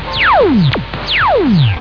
Laser Zap!
zaps.wav